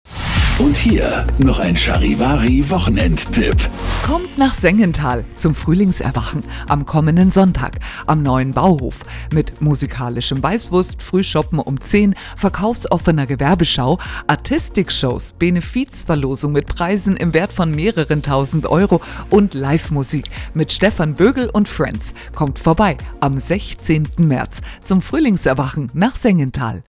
DIE EINLADUNG - EIN SPOT
_as_FRUEHLINGSERWACHEN_SPOT produziert.mp3